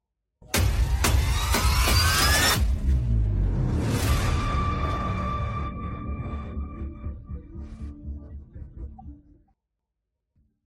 Thể loại: Hiệu ứng âm thanh
Description: Tải về các hiệu ứng âm thanh SFX, sound effect của Lê Tuấn Khang – Những âm thanh căng thẳng, hồi hộp, và kích tính... 4 phong cách quen thuộc mà anh thường sử dụng.